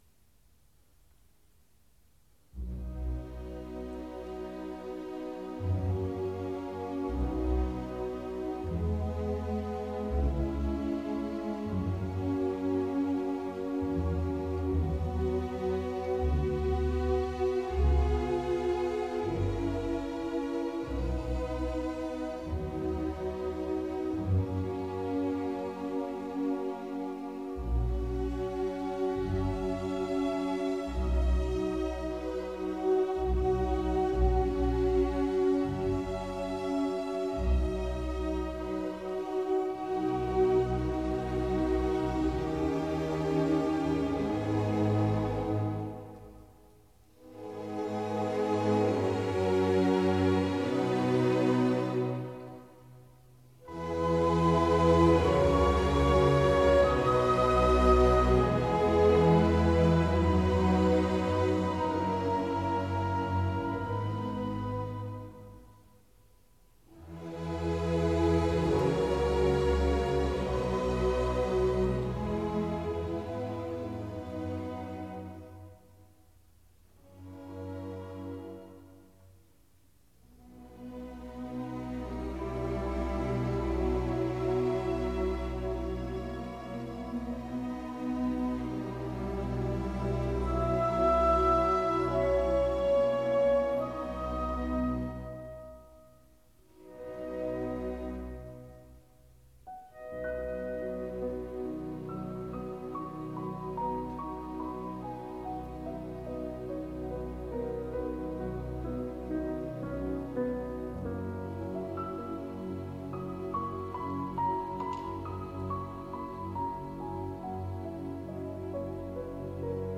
录音地点：柏林耶稣基督大教堂
乐队非常大气，声低异常浑厚。由于是单声道录音，钢琴音色显得不够晶莹透亮（如巴克豪斯的decca版钢琴就要相对光彩很多）。